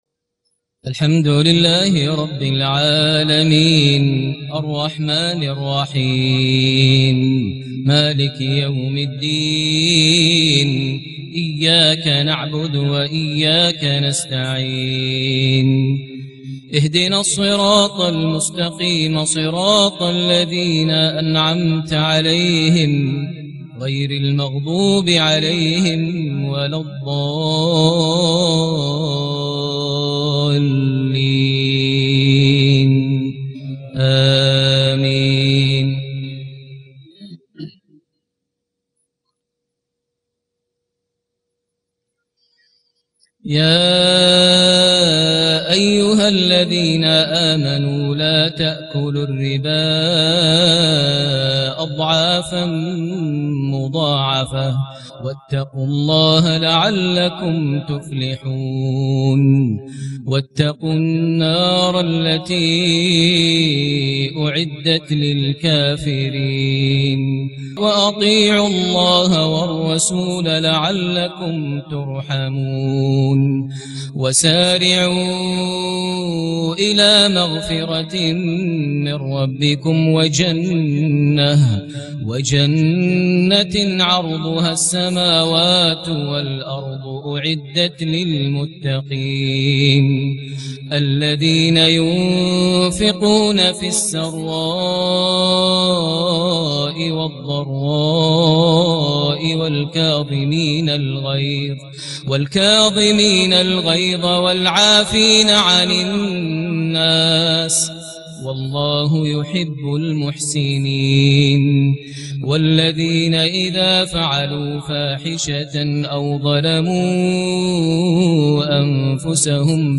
صلاة العشاء٢٣ ذو القعدة ١٤٣٨هـ سورة آل عمران ١٣٠-١٤٢ > 1438 هـ > الفروض - تلاوات ماهر المعيقلي